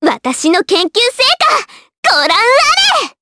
Annette-Vox_Skill5_jp.wav